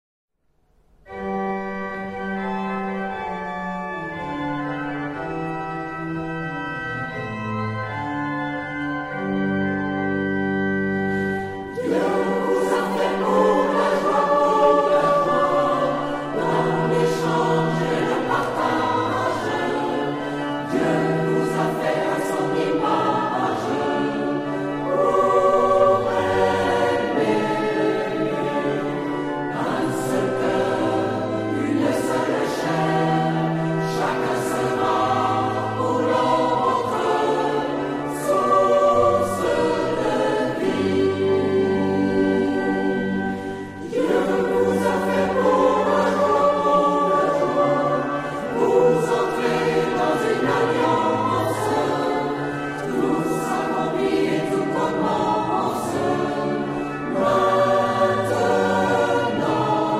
Genre-Style-Forme : Hymne (sacré)
Caractère de la pièce : joyeux ; vivant
Type de choeur : SATB  (4 voix mixtes )
Instruments : Orgue (1)
Tonalité : sol majeur